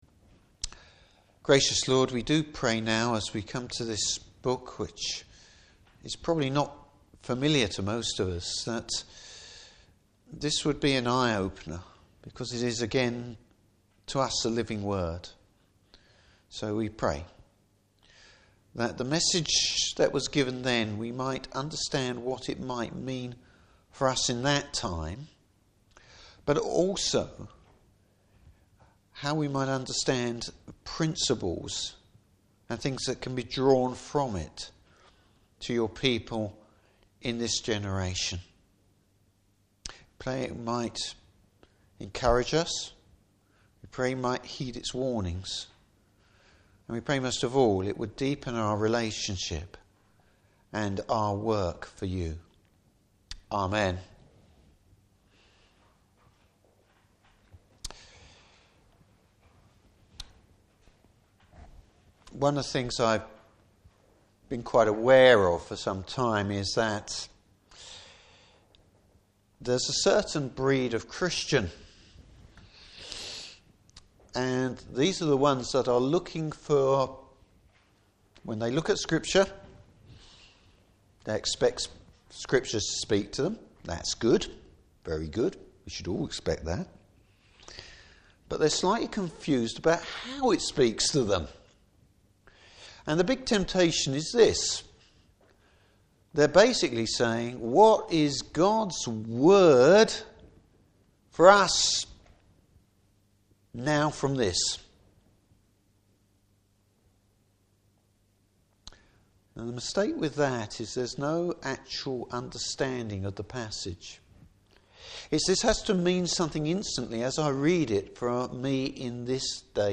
Service Type: Evening Service A national disaster raises some big questions for God’s people!